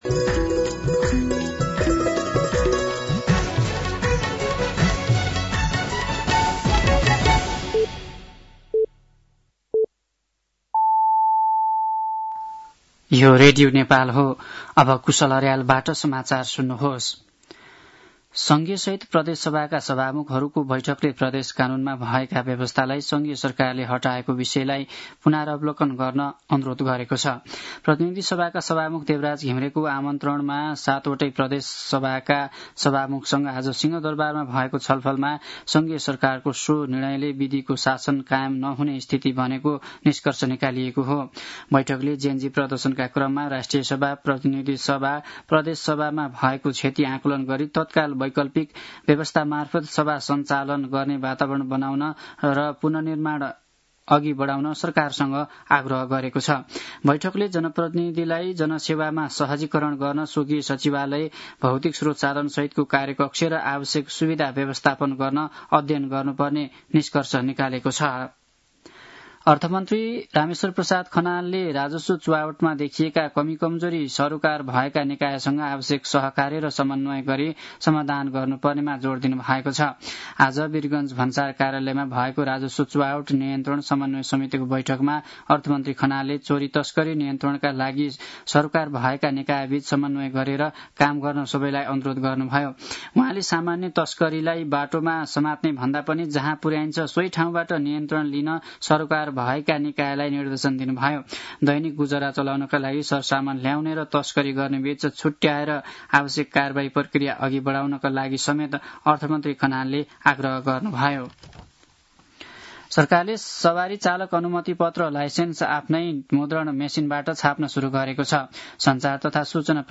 साँझ ५ बजेको नेपाली समाचार : २१ कार्तिक , २०८२